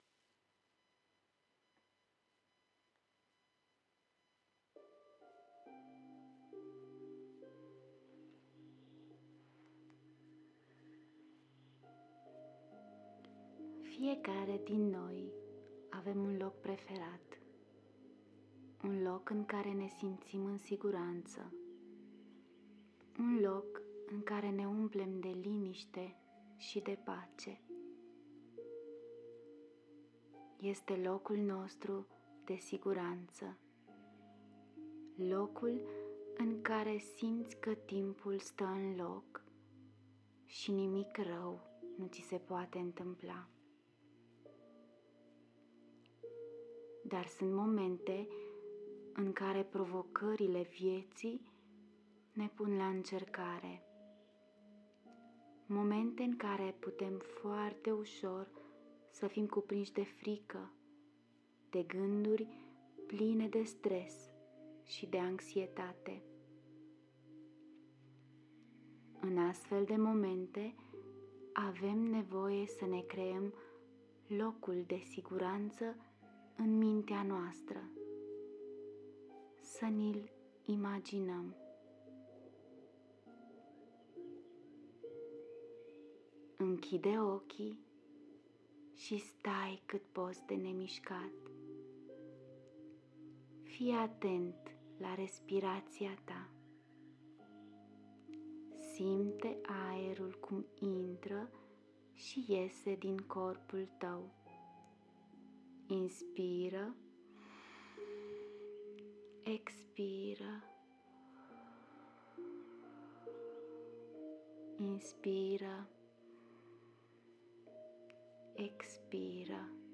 Ascultă meditația ghidată: „Locul de siguranță” https